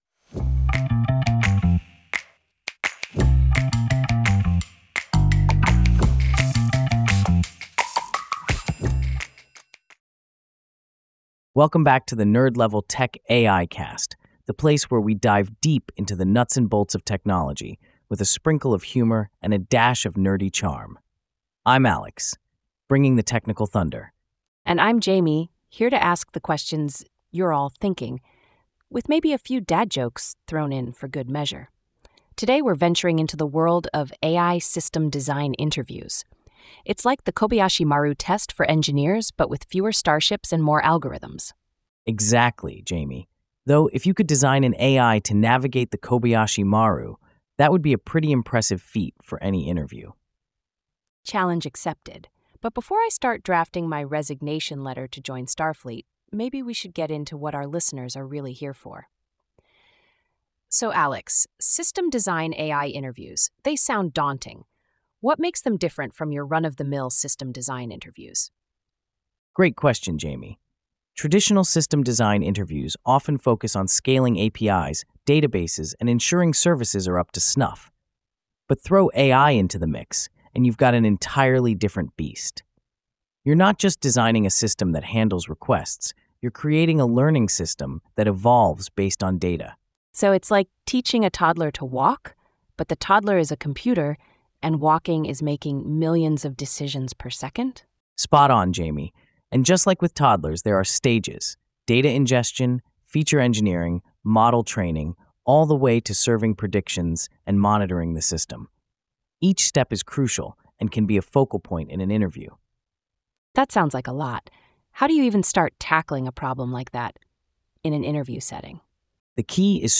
مناقشة مُولَّدة بواسطة AI من قبل Alex و Jamie